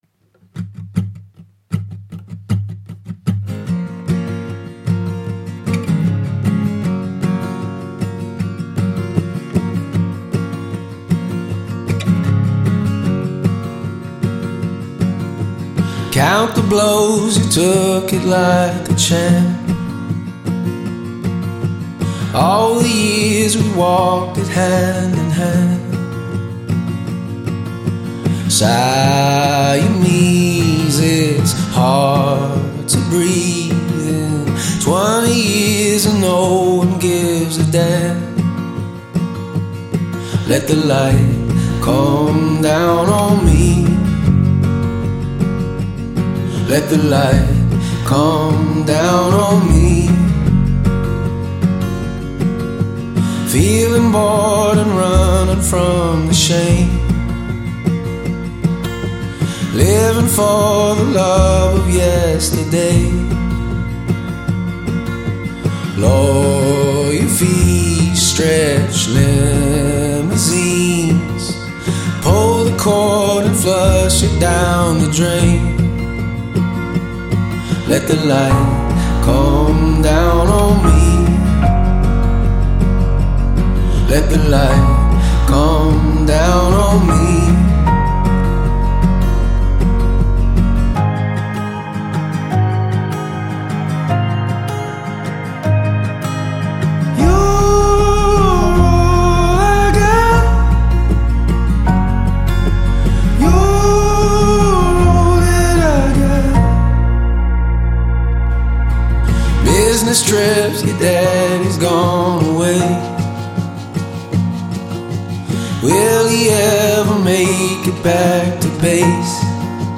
Модная инди фолк рок группа